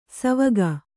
♪ savaga